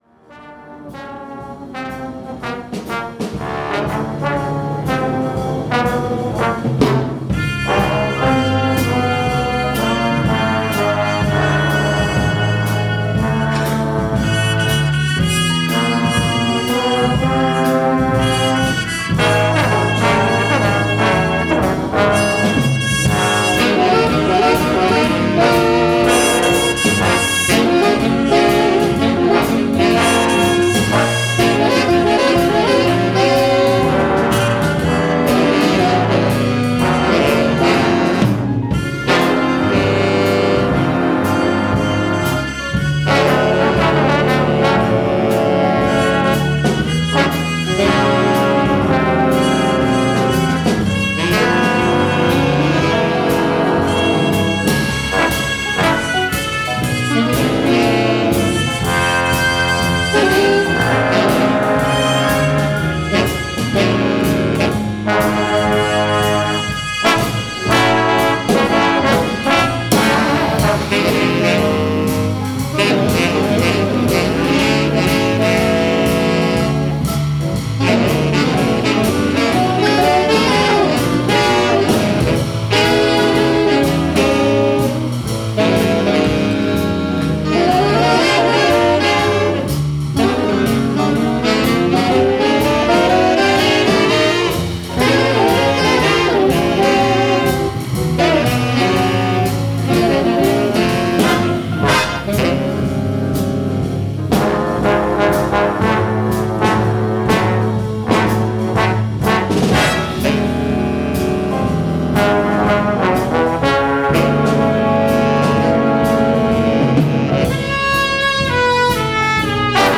TEMPLE BIG BAND
Live Z-salen!